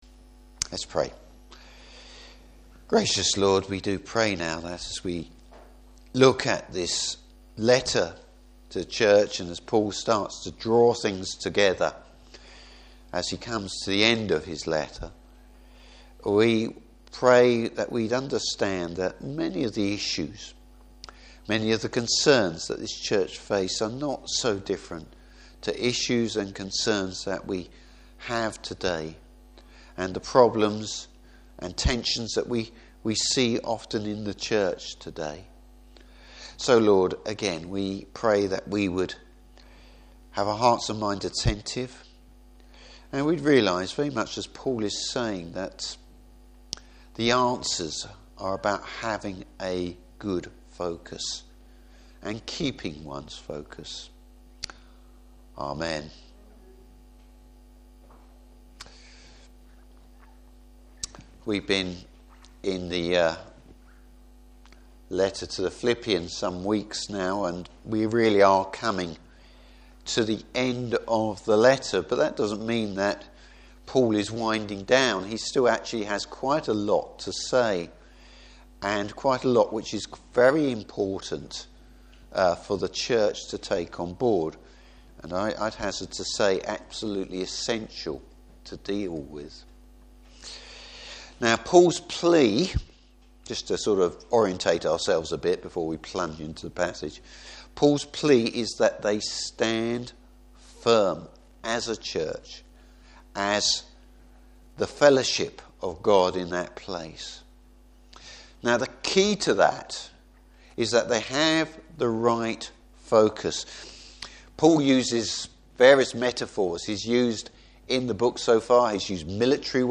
Service Type: Evening Service Staying focus on God’s Word.